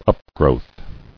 [up·growth]